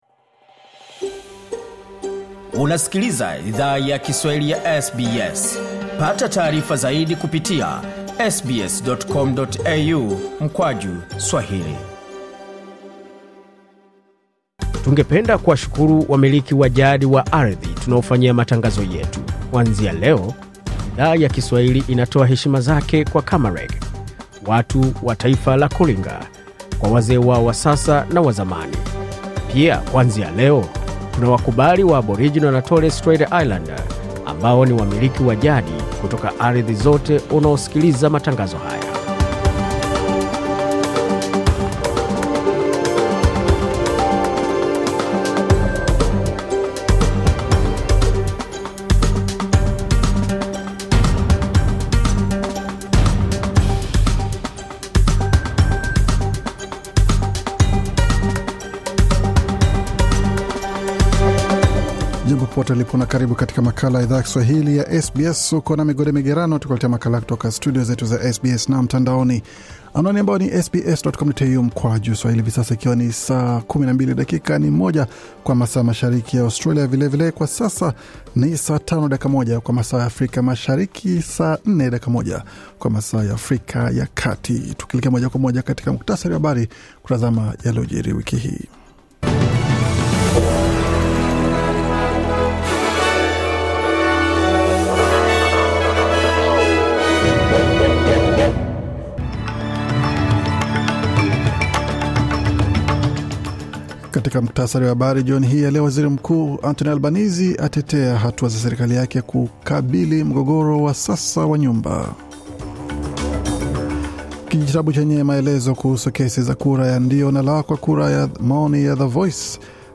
Taarifa ya Habari 27 Juni 2023